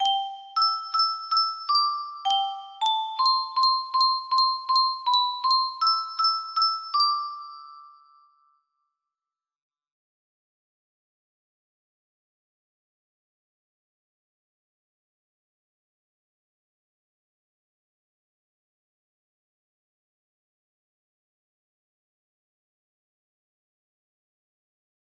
Unknown Artist - Untitled music box melody
Hey! It looks like this melody can be played offline on a 20 note paper strip music box!